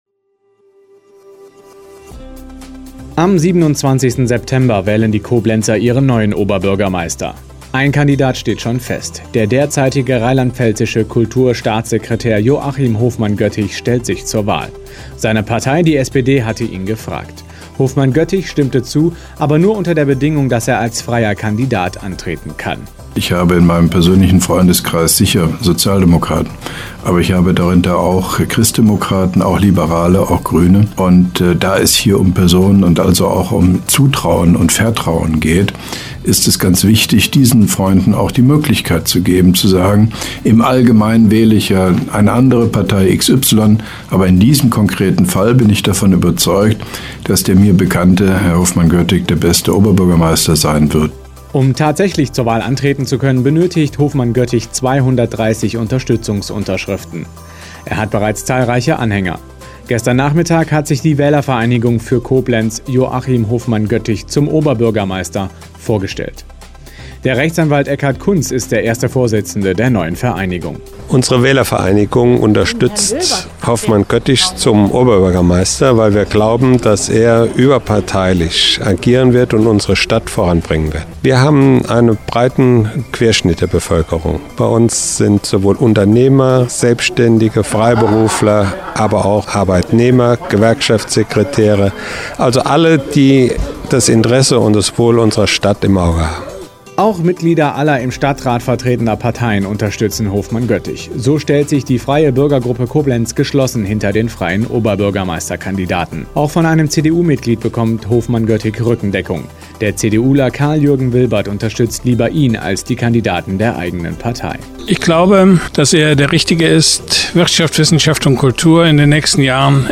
Rundfunkinterview mit Hofmann-Göttig zur Wählerinitiative „Wählervereinigung….“